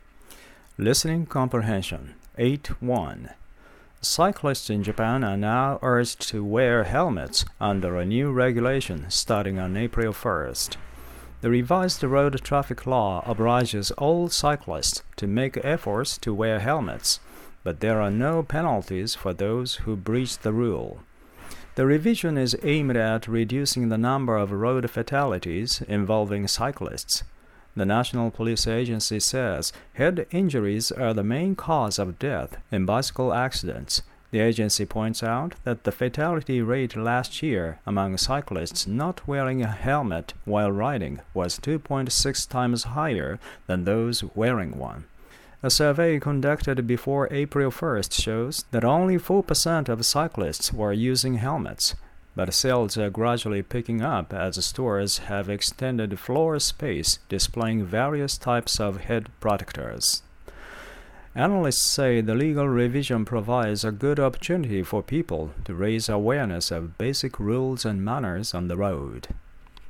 これは著作権の関係で僕が読んでいますが